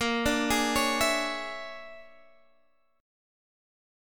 Bb7#9b5 chord